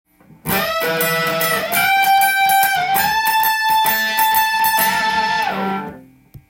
オリジナルギターtab譜　key Am
同じ音程を出しながら右のフレット移動していきます。